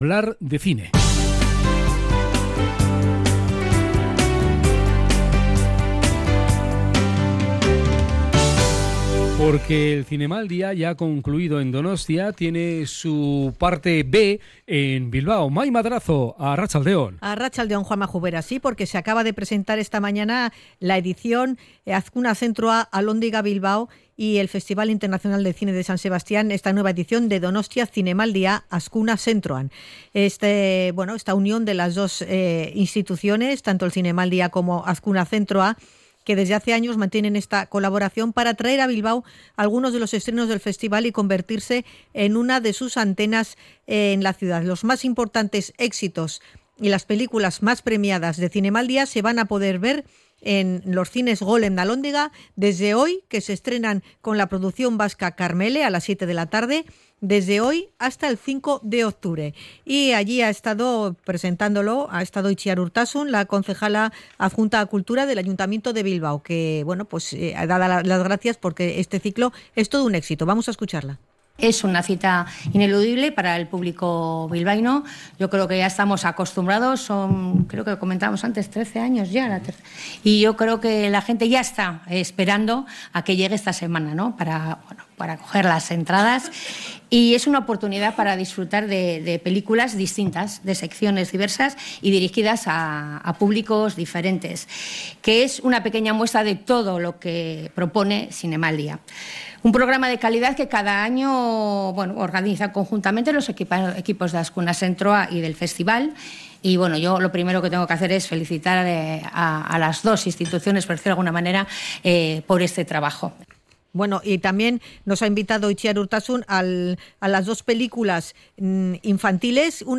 rueda de prensa de presentación